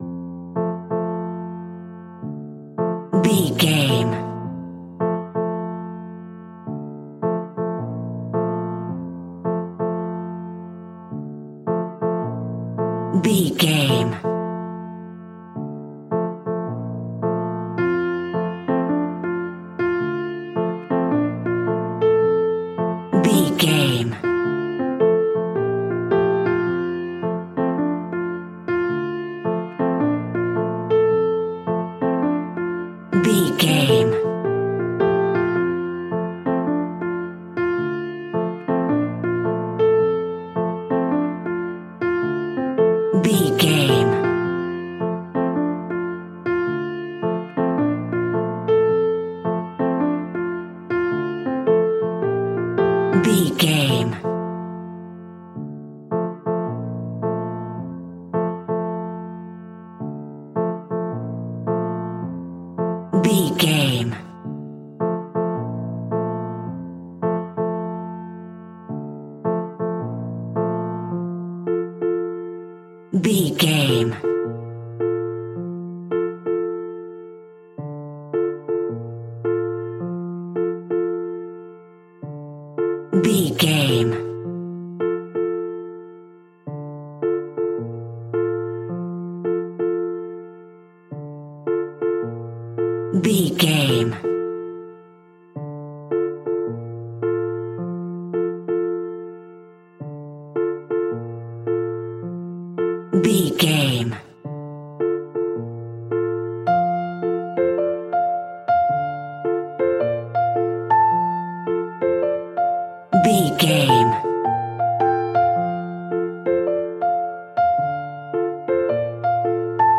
Ionian/Major
Slow
tranquil
synthesiser
drum machine